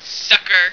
flak_m/sounds/female1/int/F1sucker.ogg at 86e4571f7d968cc283817f5db8ed1df173ad3393